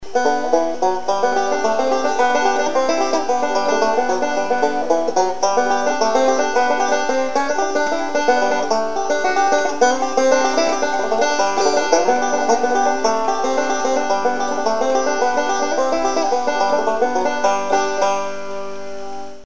Banjo
Each one is only 30 seconds or so long, just the melody line once or twice through. Played on my GoldTone BG-250F that I purchased a few months ago - a good investment that I found necessary after renting my first instrument last October.